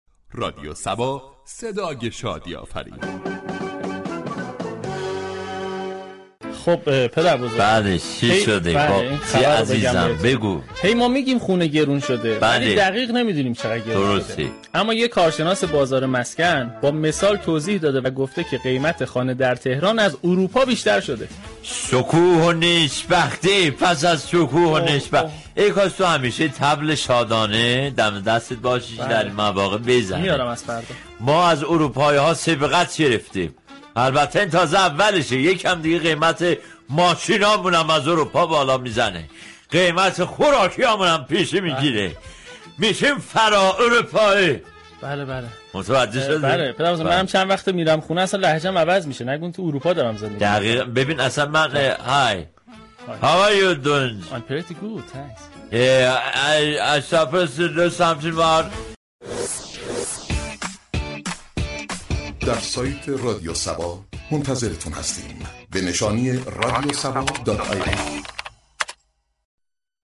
صبح صبا كه هر روز درساعت 07:00 صبح با پرداختن به موضوعات و اخبار روز جامعه ،لبخند و شادی را تقدیم مخاطبان می كند در بخش خبری با بیان طنز به خبر گرانی مسكن در پایتخت پرداخت.